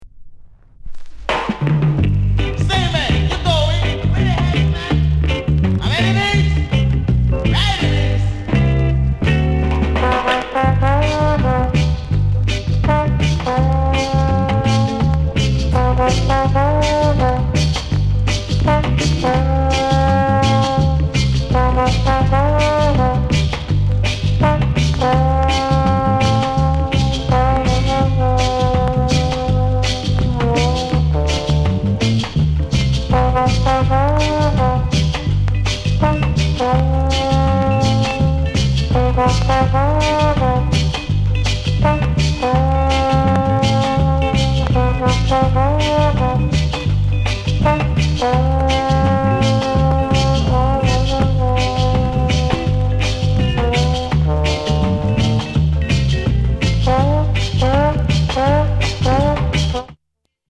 NICE SKINHEAD INST